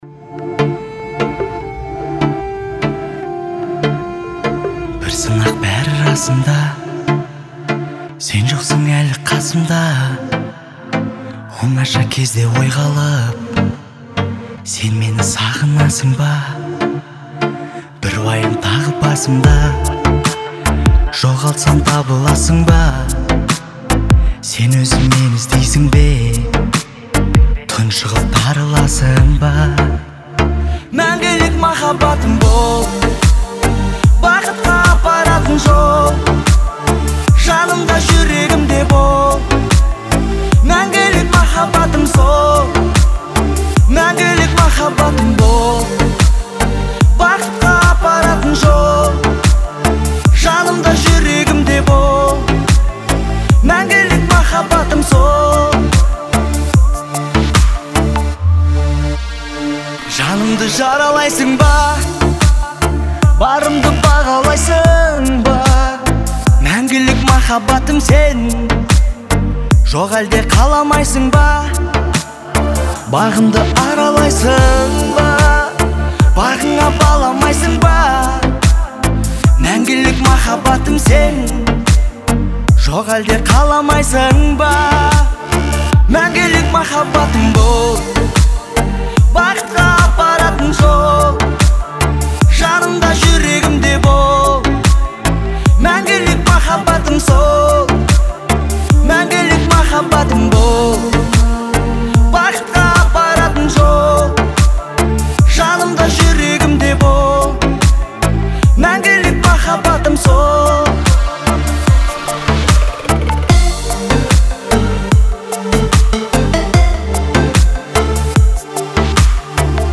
это трогательная песня в жанре казахской поп-музыки